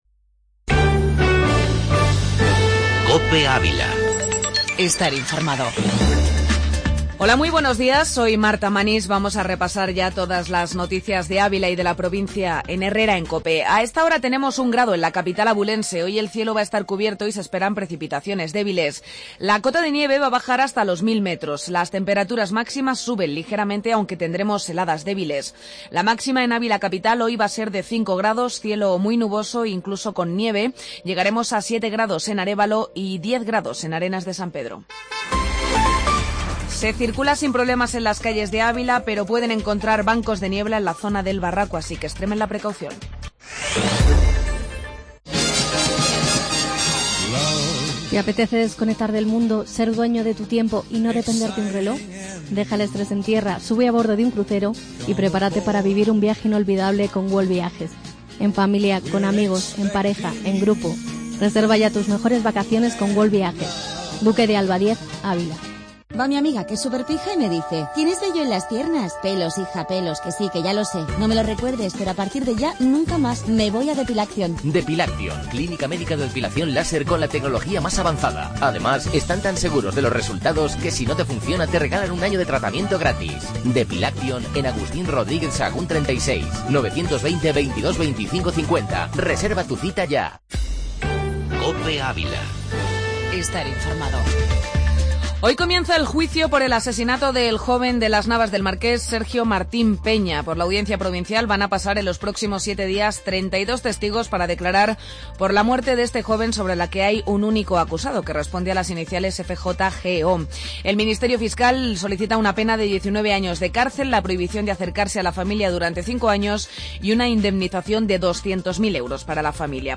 Informativo La Mañana en Ávila